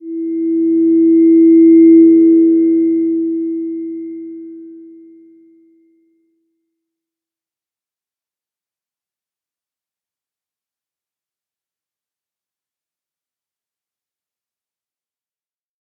Slow-Distant-Chime-E4-p.wav